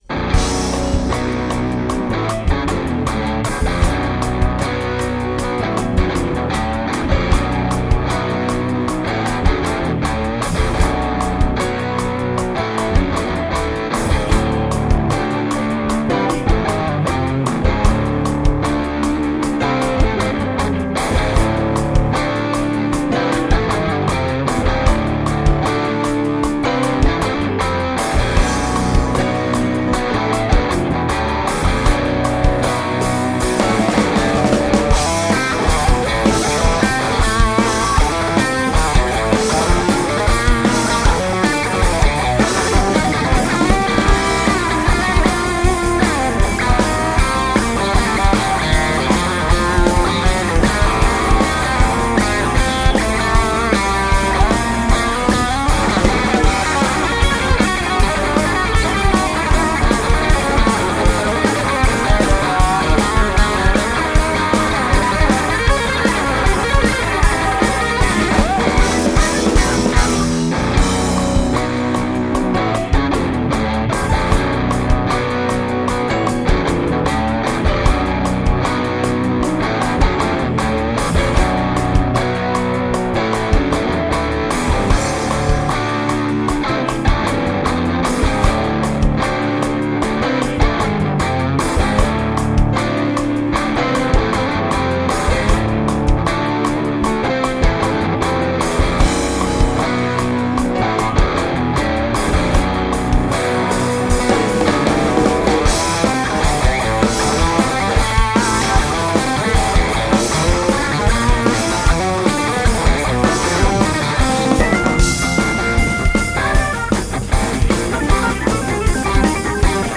фьюжн